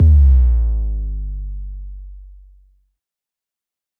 Digi Slide 808 - SSO.wav